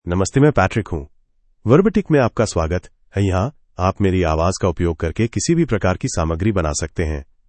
Patrick — Male Hindi (India) AI Voice | TTS, Voice Cloning & Video | Verbatik AI
Patrick is a male AI voice for Hindi (India).
Voice sample
Listen to Patrick's male Hindi voice.
Patrick delivers clear pronunciation with authentic India Hindi intonation, making your content sound professionally produced.